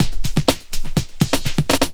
21 LOOP08 -L.wav